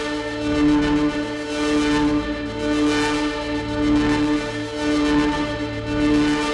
Index of /musicradar/dystopian-drone-samples/Tempo Loops/110bpm
DD_TempoDroneB_110-D.wav